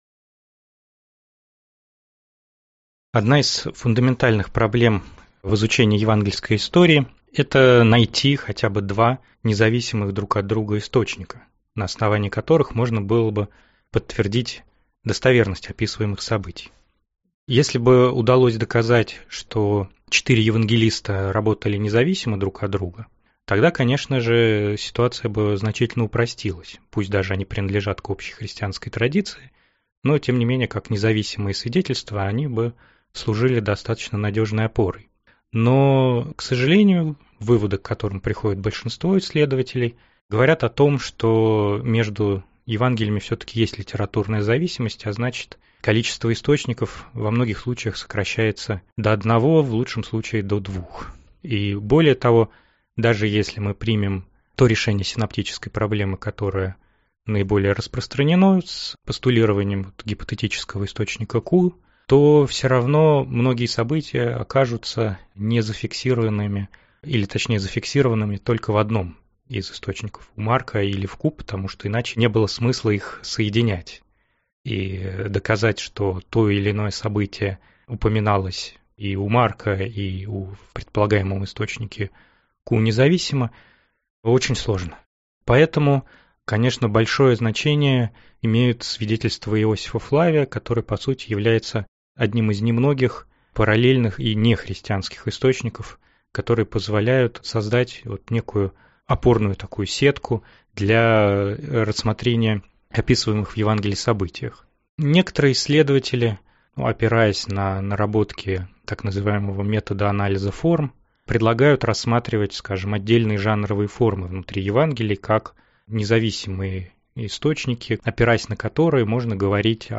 Аудиокнига Иоанн Креститель. Рождество и Крещение Иисуса | Библиотека аудиокниг